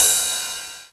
cch_perc_cymbal_high_bright_rider.wav